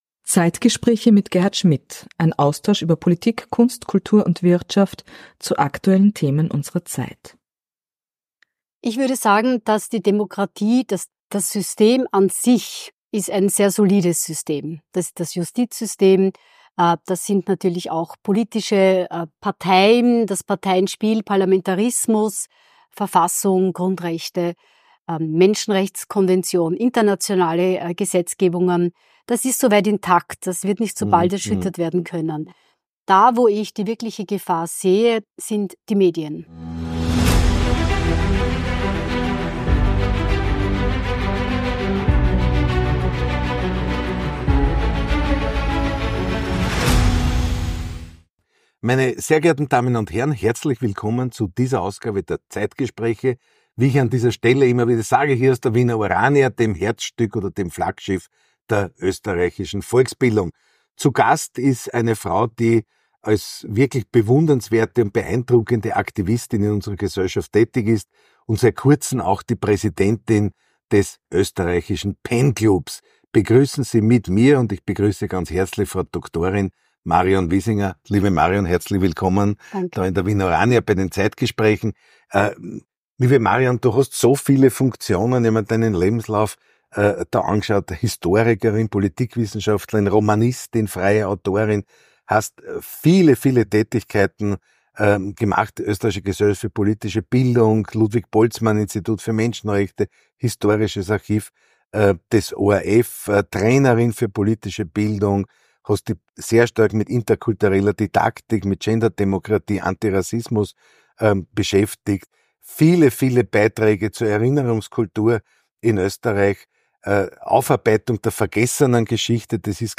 Zeit für Gespräche – Zeit für Antworten.